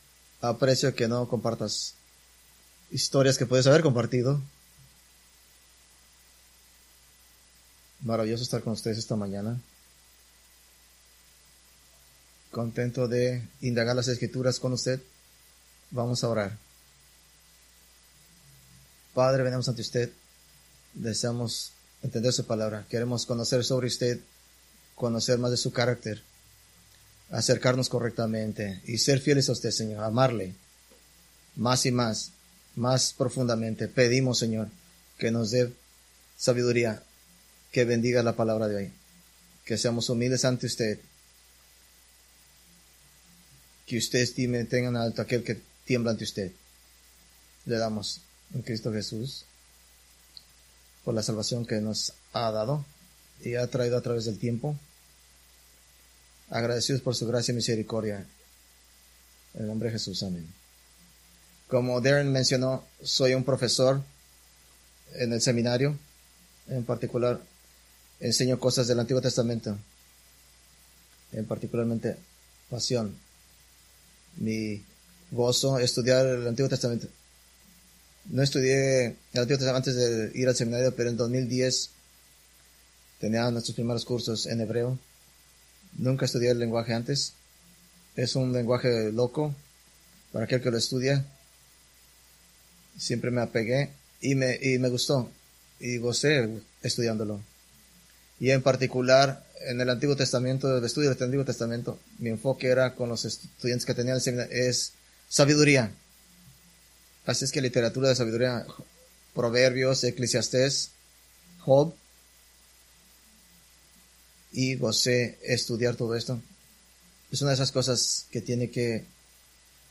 Preached July 28, 2024 from Proverbs 1:7